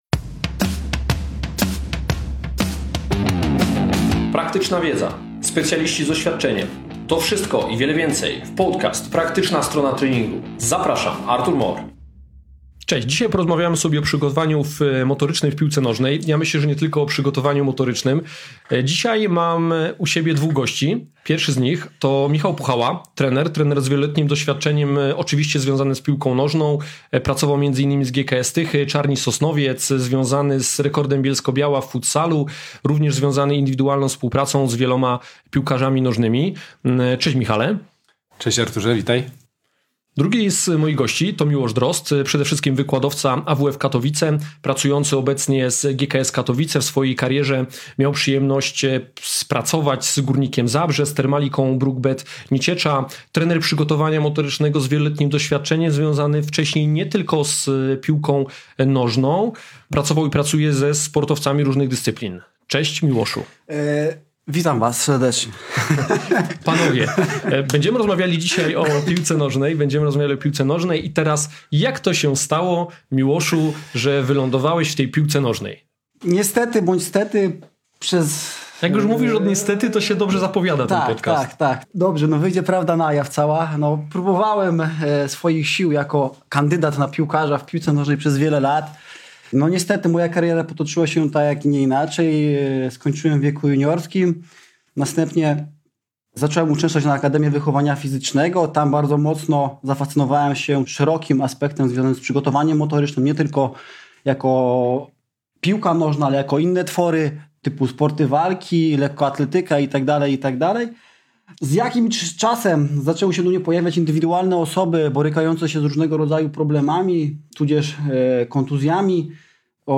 Nie sposób napisać ilu nieoczywistych rzeczy dowiesz się z tej rozmowy.